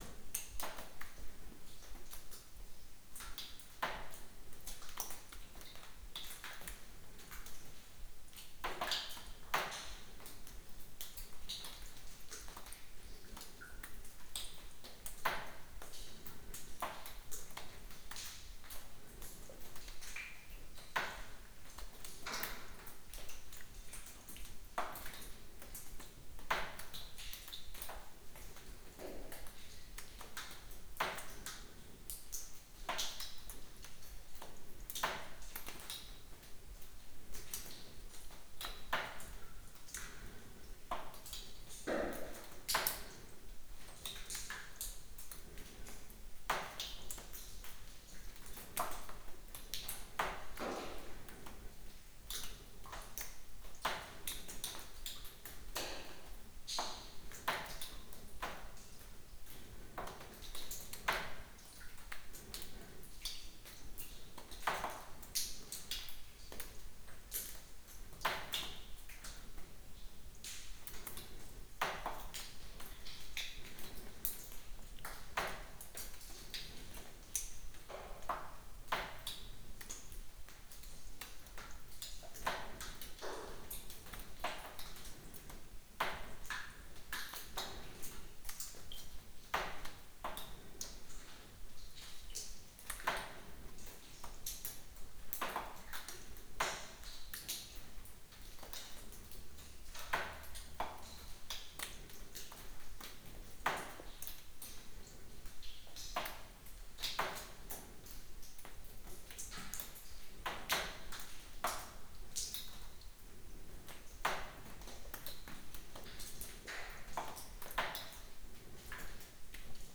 Tonaufnahmen mit der Kunstkopf-Aufnahmetechnik aus dem Hause "Soundman" liefern ein Klangerlebnis, exakt so, wie der Mensch hört.
Mit keiner anderen Aufnahmetechnik erreicht man diese hohe Klangqualität, bei zusätzlich 360 Grad räumlicher Akustik.
Sophienhoehle-2-1.wav